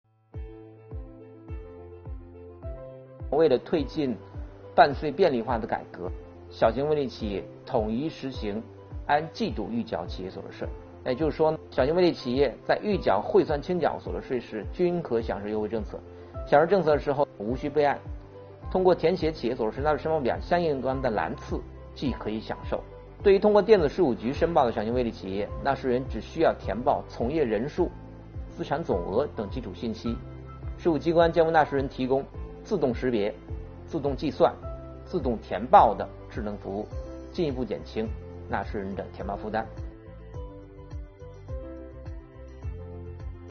近日，国家税务总局推出最新一期“税务讲堂”课程，国家税务总局所得税司副司长王海勇介绍并解读了小型微利企业和个体工商户所得税优惠政策。